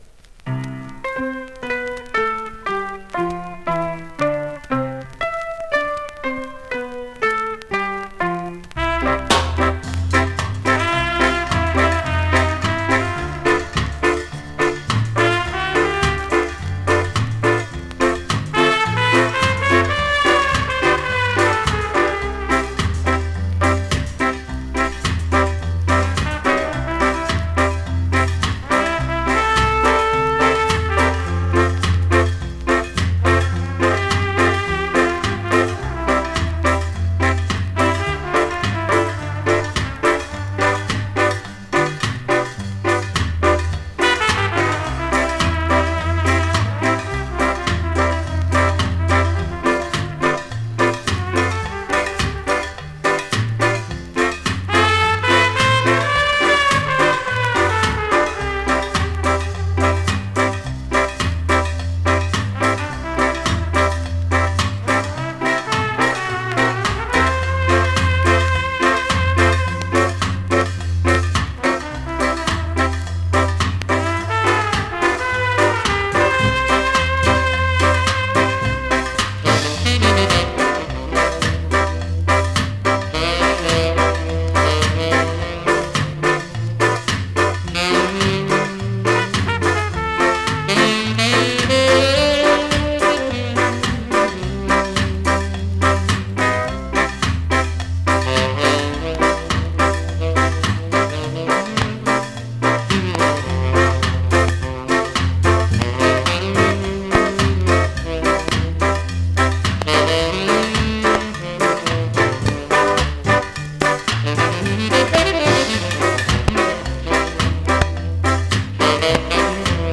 ※紙の混入や後半の針飛びループ有
後半にダメージ有針飛びループします)